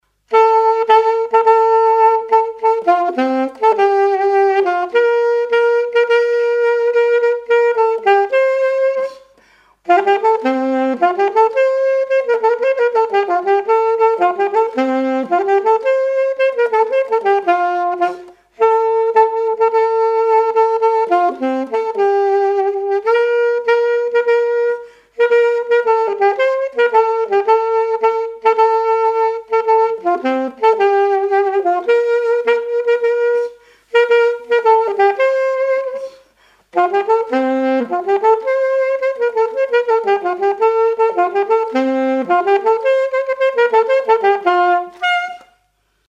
Mémoires et Patrimoines vivants - RaddO est une base de données d'archives iconographiques et sonores.
danse : quadrille : pastourelle
témoignages et instrumentaux
Pièce musicale inédite